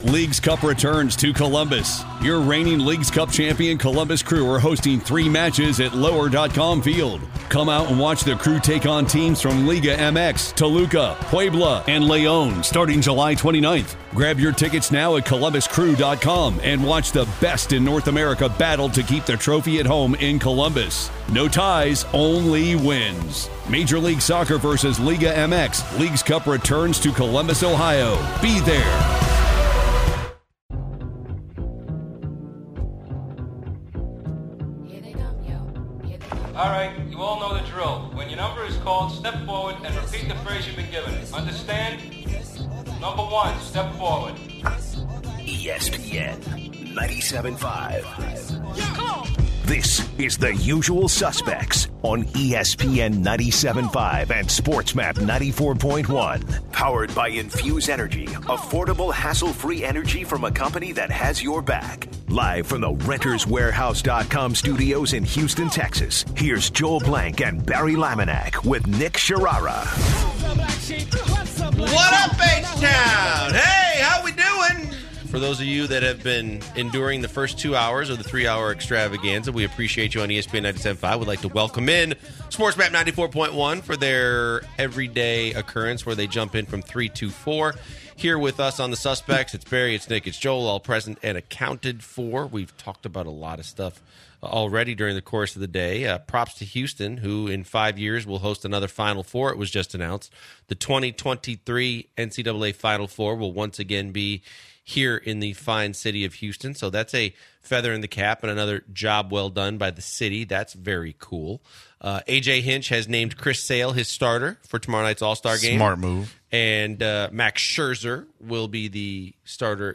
To start off the hour, the guys talk about their opinions on the mid-season awards. They take in a call about Le’Veon Bell and how the Steelers have not agreed on a contract extension. They discuss their opinions on the Texans adding Jamaal Charles for depth, eating in the bathroom, and how John Schnatter (Papa John’s founder) got kicked out of his office for his racist scandal.